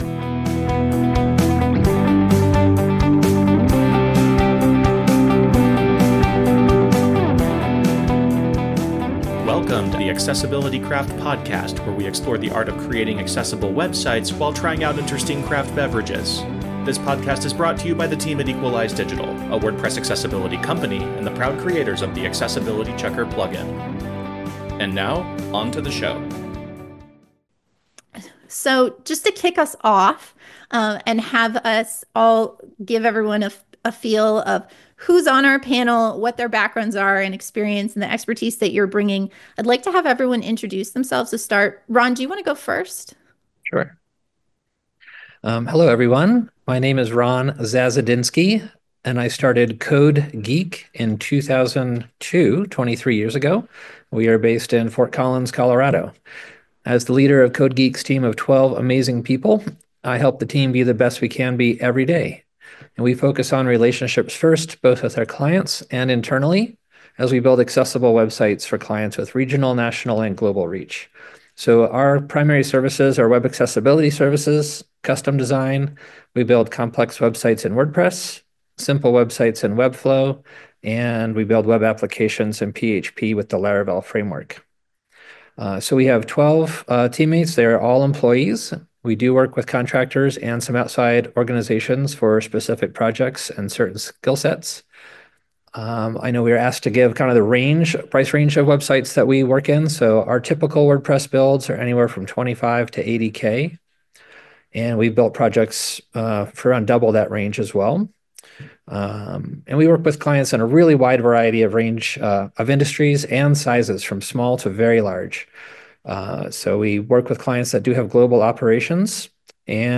Selling Accessibility Panel Discussion with WordPress Agency Owners
This episode is a recording of a May 2025 WordPress Accessibility Meetup where several WordPress agency owners weighed in with their own strategies and success stories on selling accessibility.